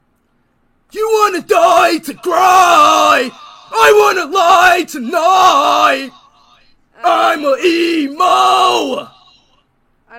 Emo Scream Sound Effect Free Download
Emo Scream